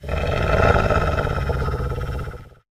wolf-growl-1.ogg